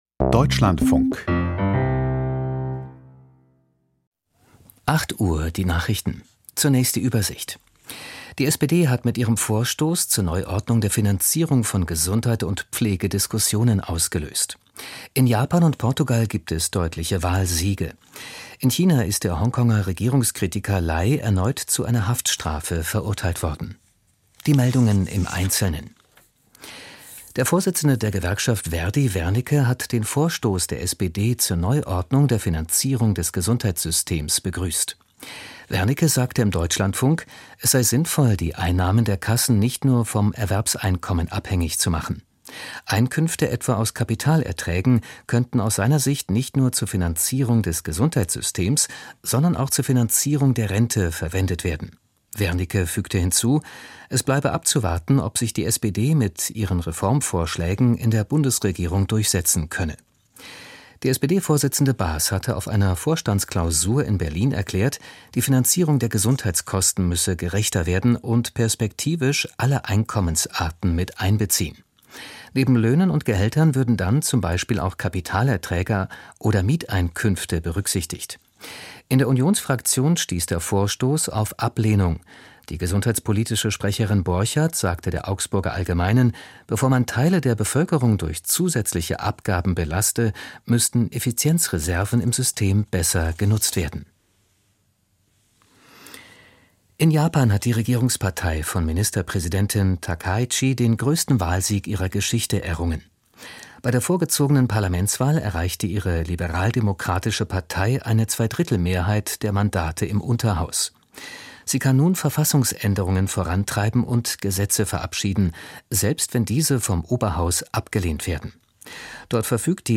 Die Nachrichten vom 09.02.2026, 08:00 Uhr